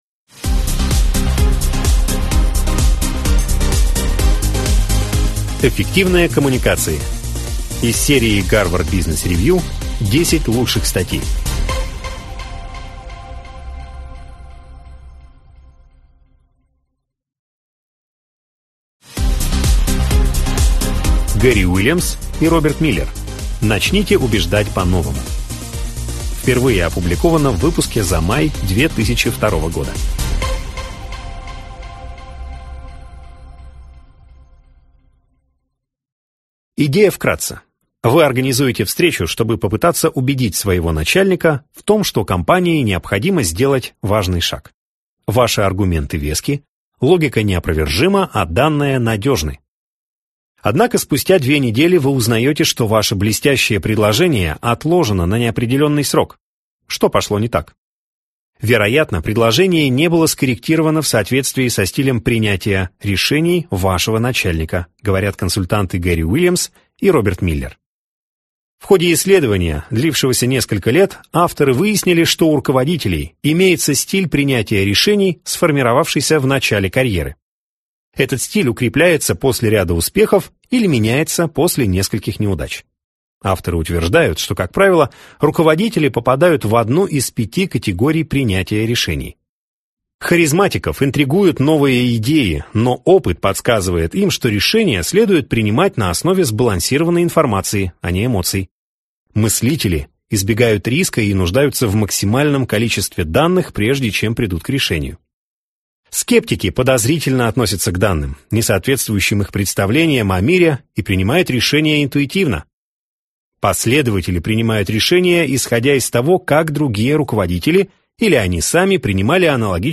Аудиокнига Эффективные коммуникации | Библиотека аудиокниг